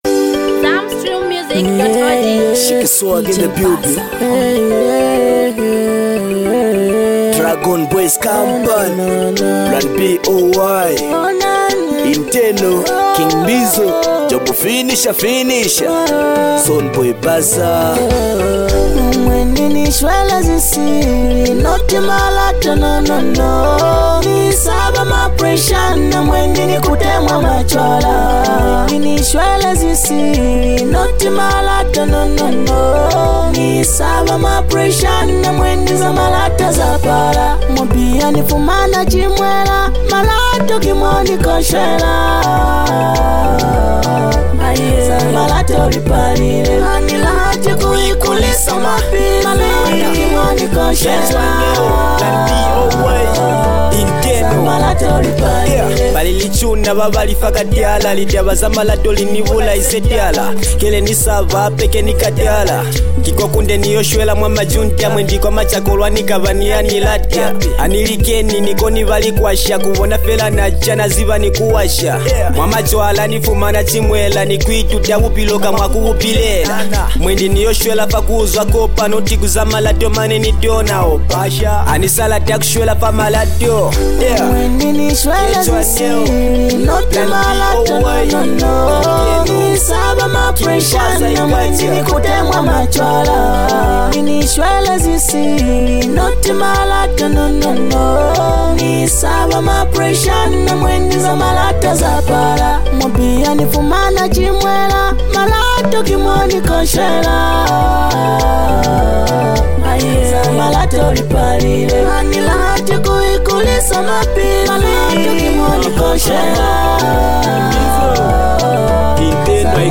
energetic and captivating track
a song filled with rhythm, emotion, and cultural flavor